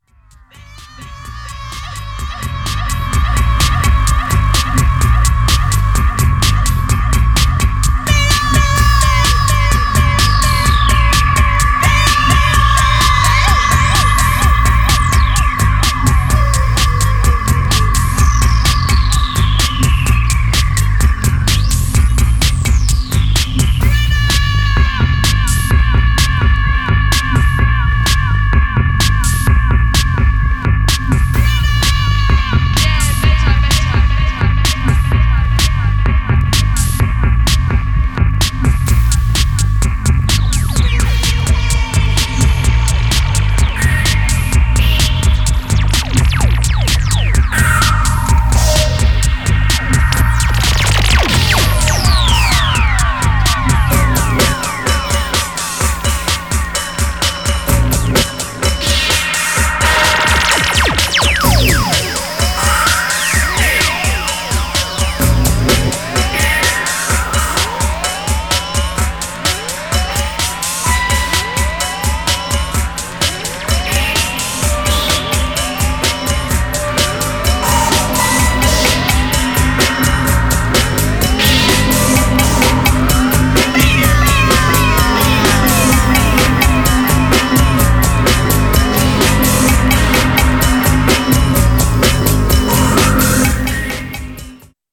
Styl: Electro, House, Breaks/Breakbeat